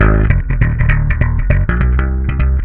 SLAPBASS2 -L.wav